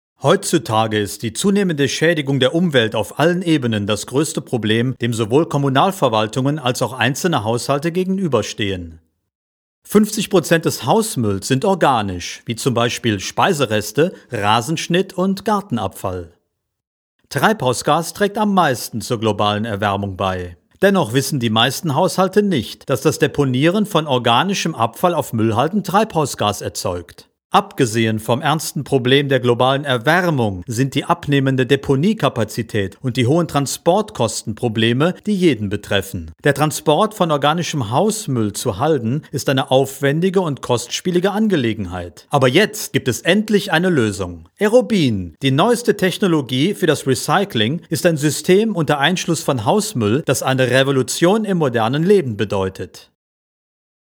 Sprechprobe: Industrie (Muttersprache):
German voice over talent and musician